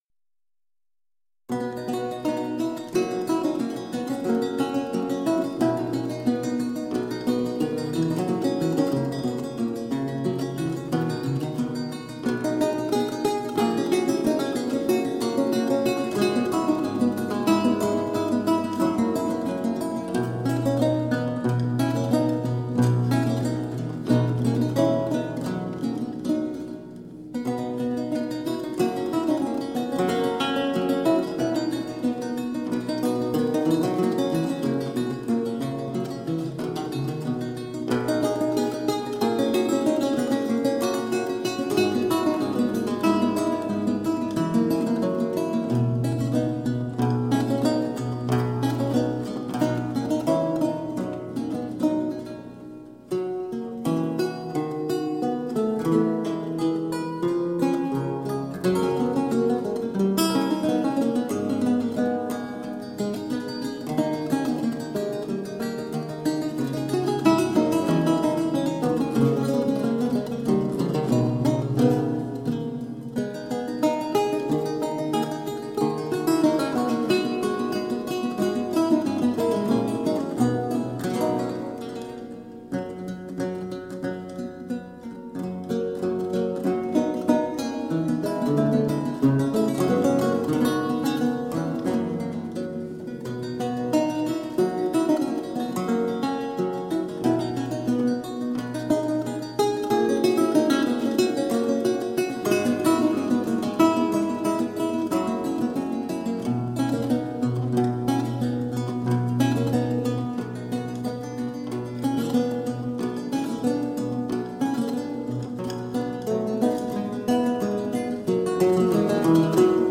Vihuela, renaissance and baroque lute.